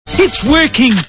The Simpsons [Burns] Cartoon TV Show Sound Bites